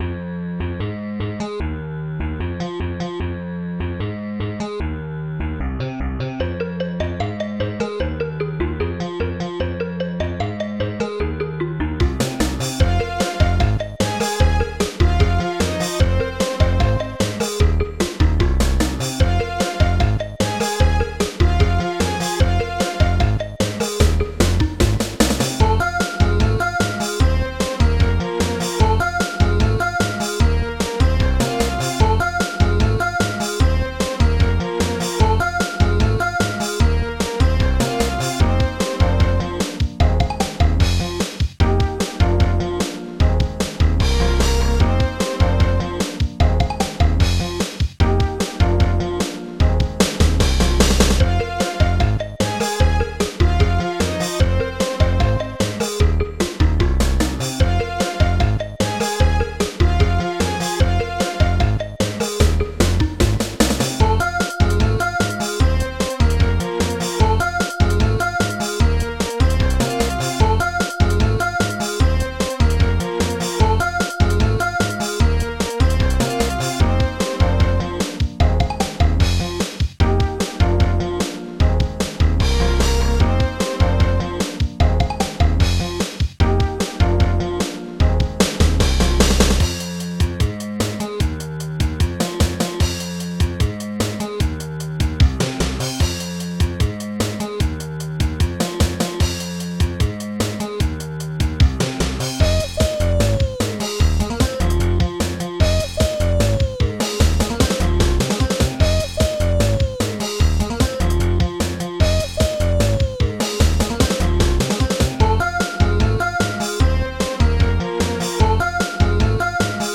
Protracker Module
D10bassdrum1 D10clohihat1 D10snare3 D10crashcymbal bass-4 xylophone tremelo-brassbell D50-bass1 D50-do-chord-1 d50-string1 D50-reso-ahh